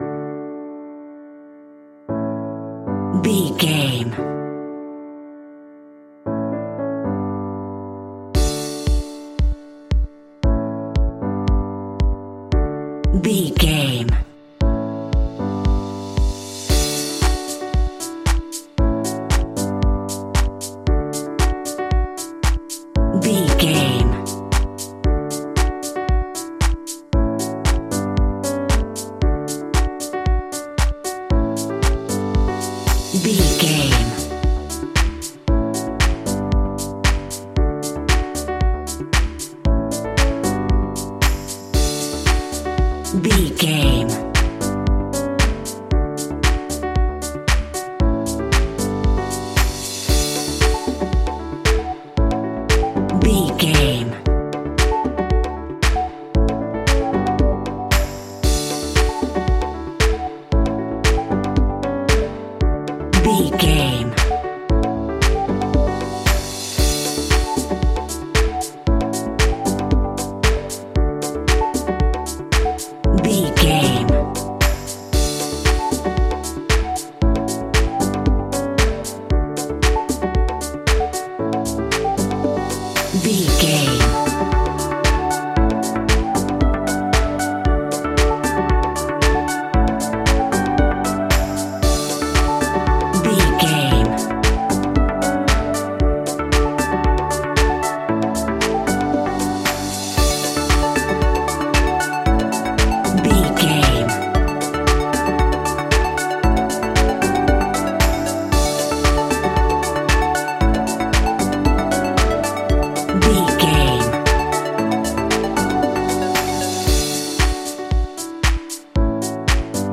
Ionian/Major
D
groovy
energetic
uplifting
hypnotic
drum machine
synthesiser
piano
house
electro house
synth pop
synth leads
synth bass